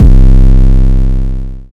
DEEDOTWILL 808 39.wav